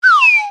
Arcade - Taiko no Tatsujin 2020 Version - Common Sound Effects
Cancel.wav